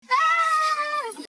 Young Carl Scream Sound Effect Download: Instant Soundboard Button